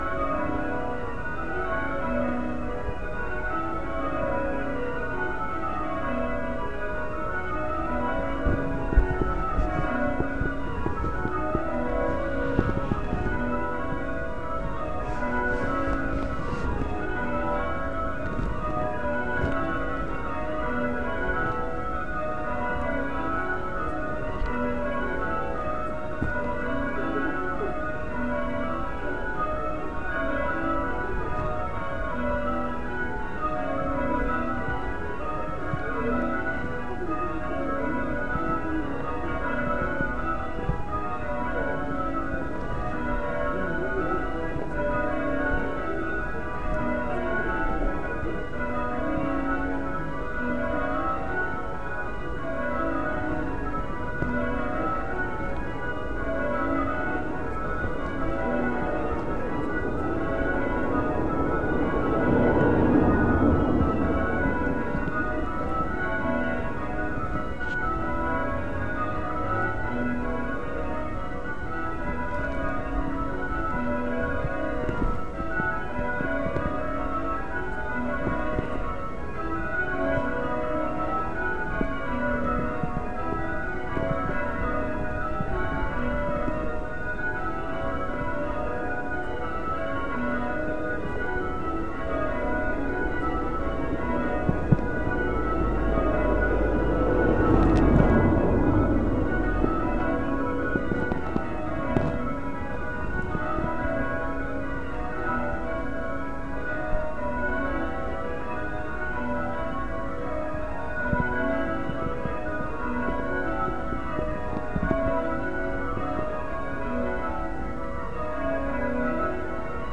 September Old St Martin's Bells 6